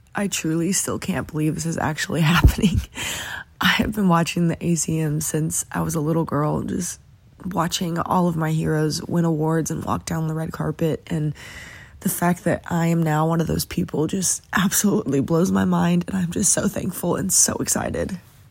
Audio / An emotional Kylie Morgan is blown away by her ACM nomination for New Female Artist of the Year at this year's ACM Awards.